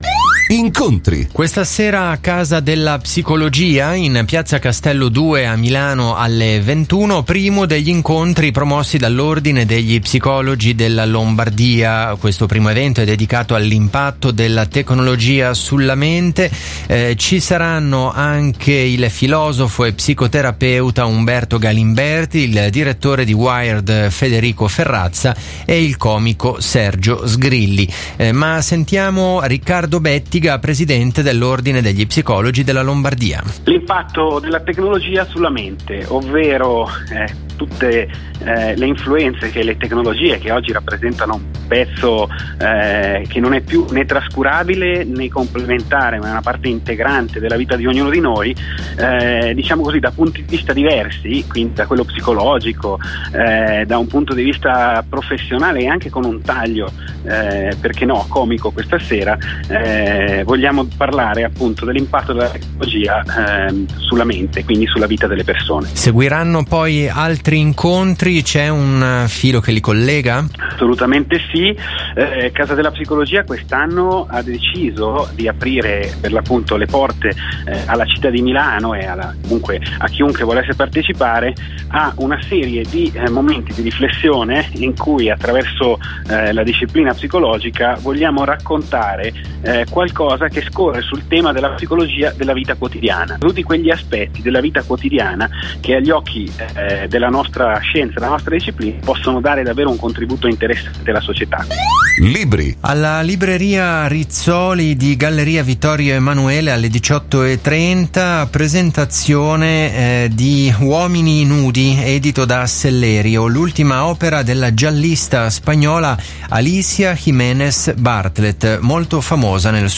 Realtà virtuale e terapie sul web, come cambia la psicologia Interviste